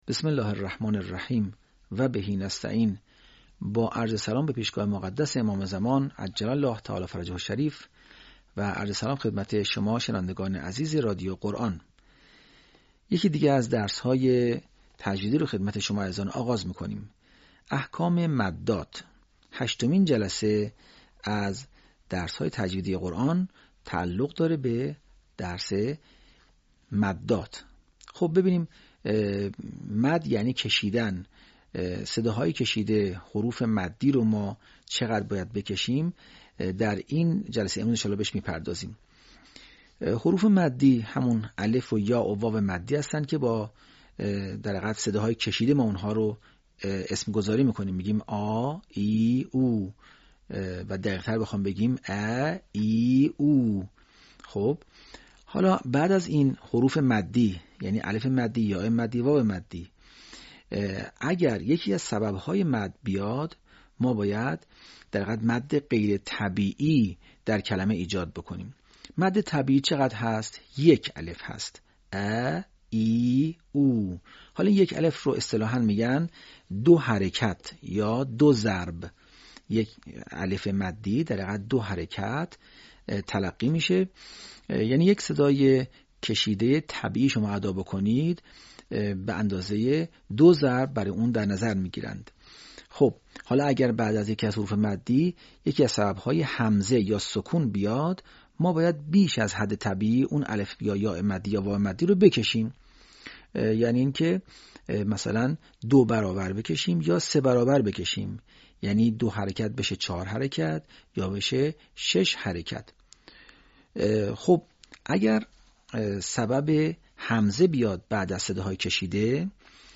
به همین منظور مجموعه آموزشی شنیداری (صوتی) قرآنی را گردآوری و برای علاقه‌مندان بازنشر می‌کند.